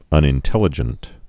(ŭnĭn-tĕlə-jənt)